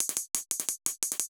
Index of /musicradar/ultimate-hihat-samples/175bpm
UHH_ElectroHatA_175-02.wav